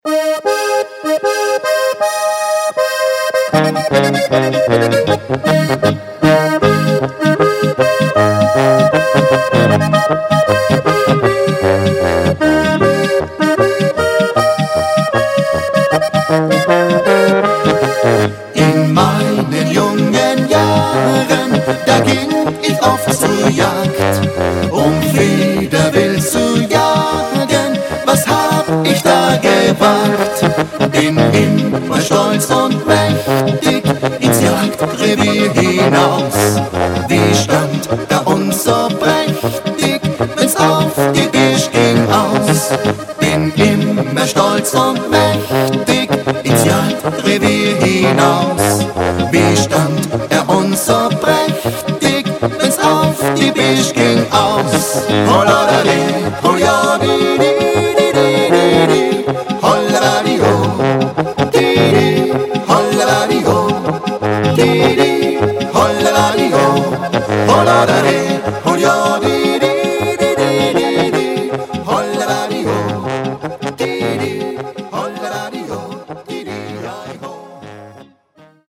VOLKSTÜMLICH